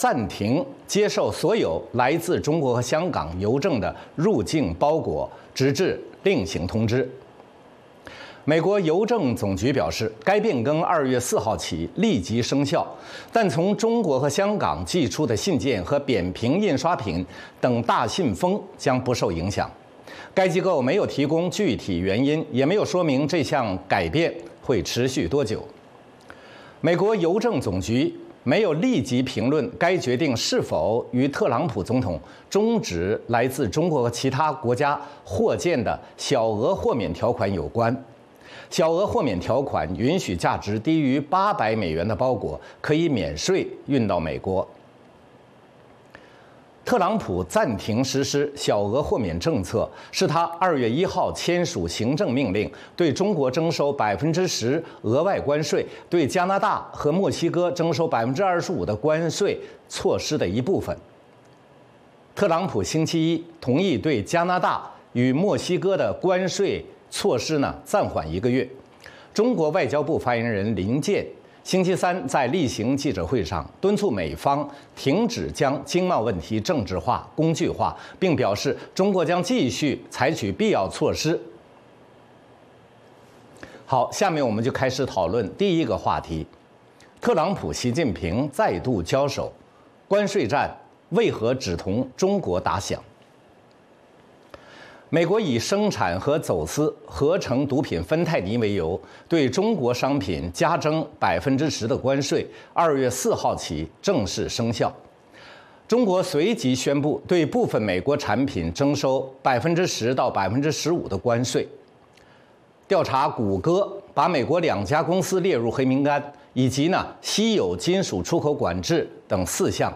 《时事大家谈》围绕重大事件、热点问题、区域冲突以及中国内政外交的重要方面，邀请专家和听众、观众进行现场对话和讨论，利用这个平台自由交换看法，探索事实。